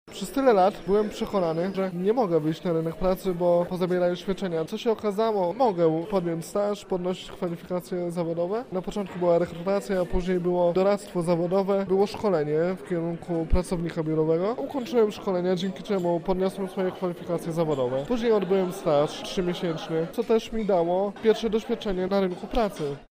O tym, jak wygląda program, mówi jeden z uczestników